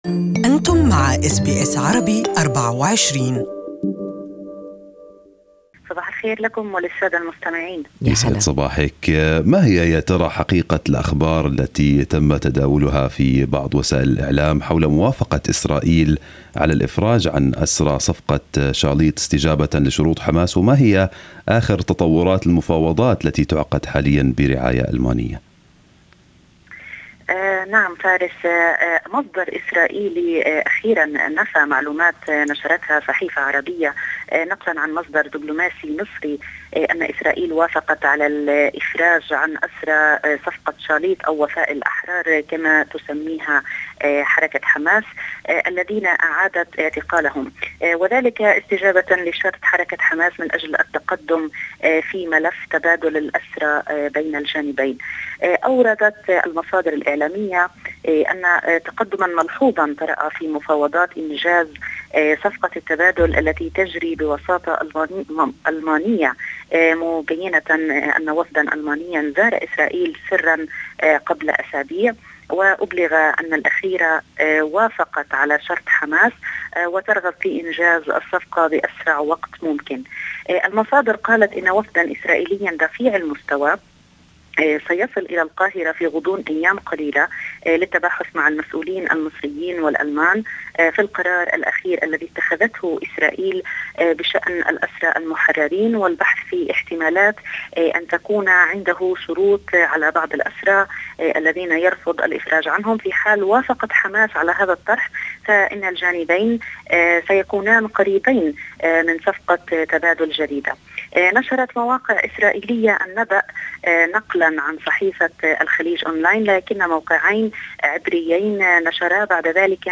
Listen to the Ramallah message in Arabic above